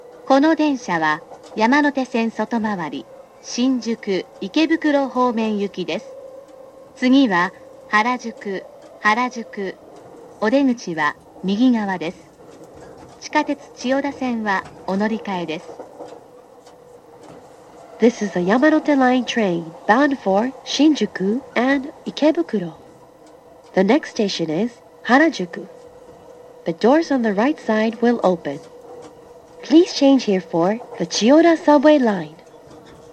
Yamanote-Harajuku-Next.mp3